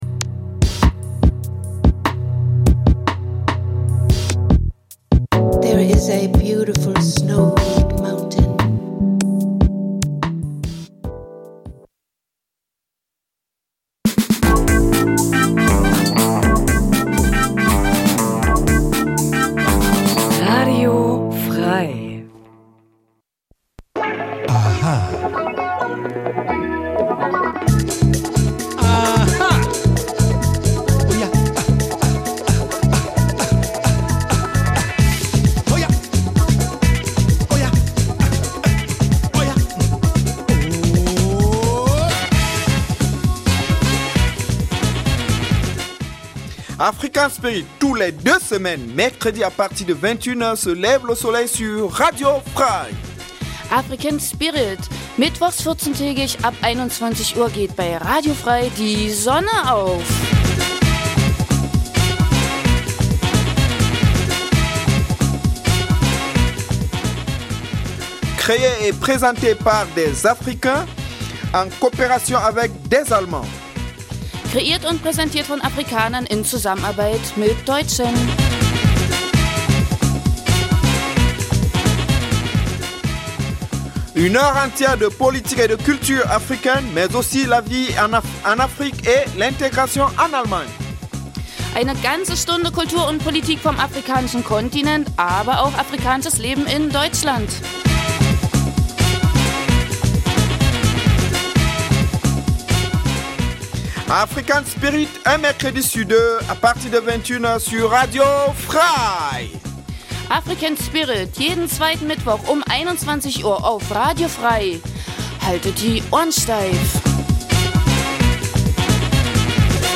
Afrikanisches zweisprachiges Magazin Dein Browser kann kein HTML5-Audio.
Die Gespräche werden mit afrikanischer Musik begleitet.